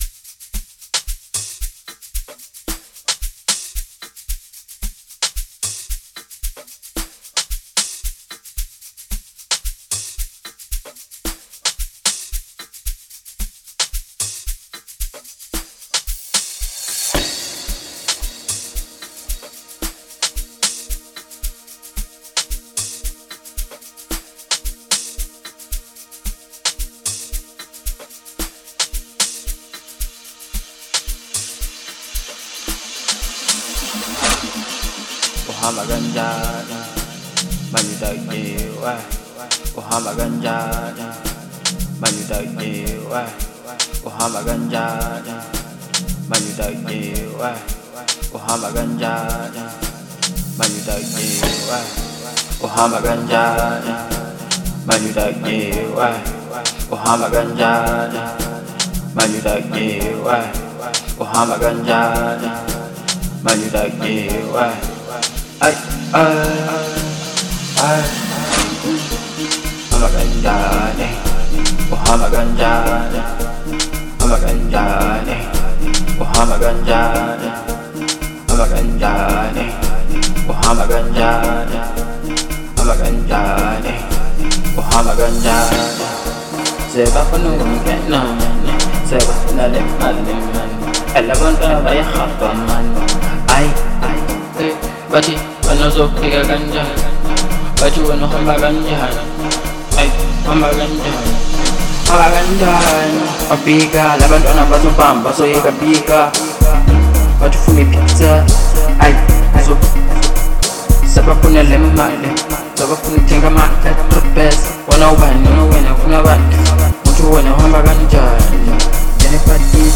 07:00 Genre : Amapiano Size